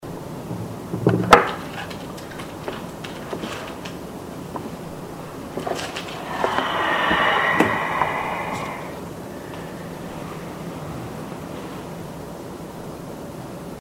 The BOO's first EVP catch